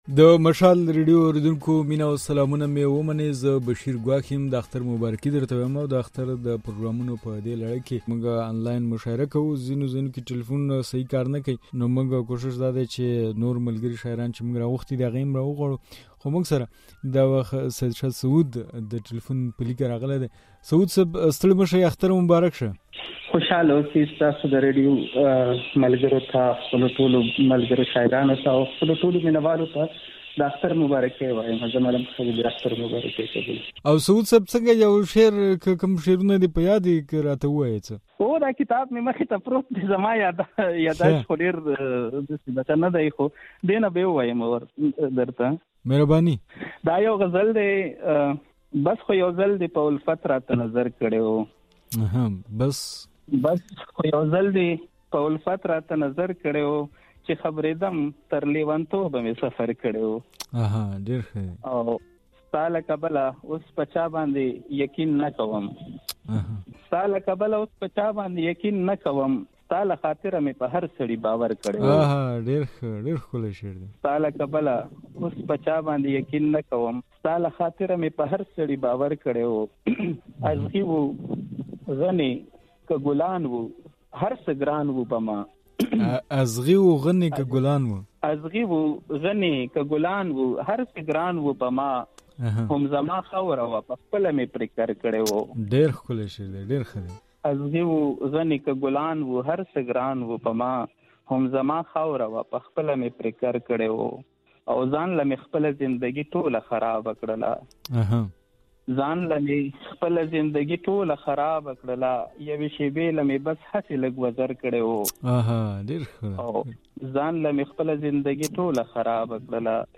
د مشال راډيو د اختر پروګرامونو په لړ کې مو مشاعره کړې ده.